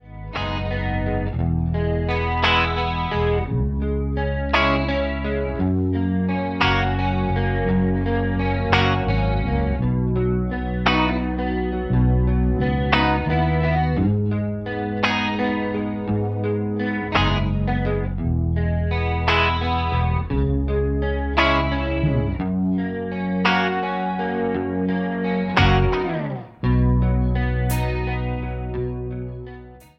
MPEG 1 Layer 3 (Stereo)
Backing track Karaoke
Pop, 2010s